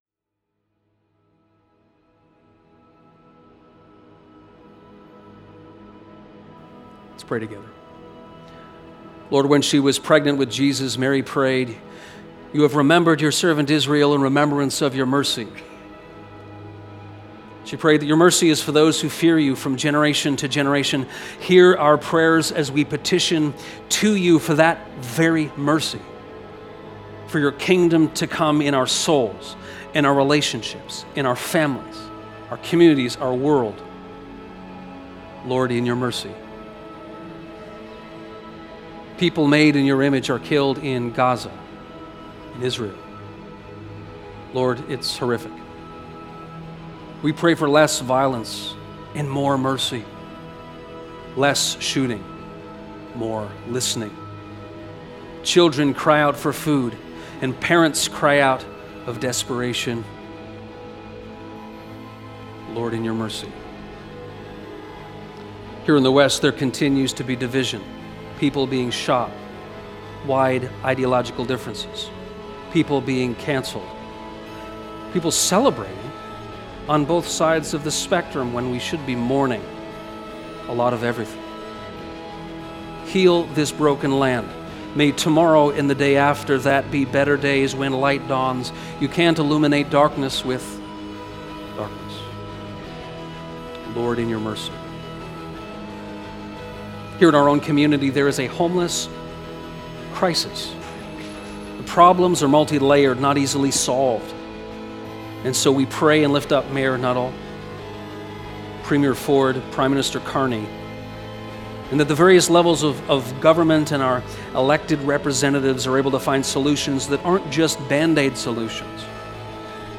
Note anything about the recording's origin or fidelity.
Join in with this prayer from the worship service on September 21